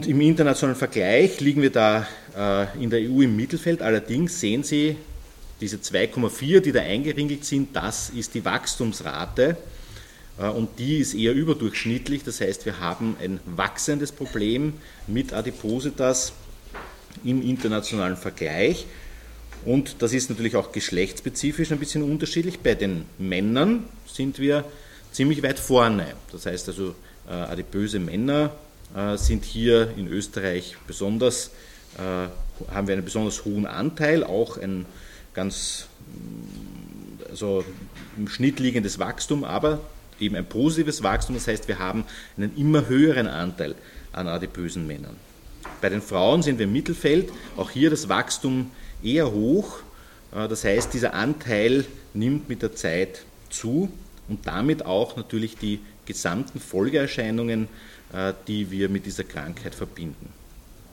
.mp3 O-Ton Dateien der Pressekonferenz vom 21.06.2022: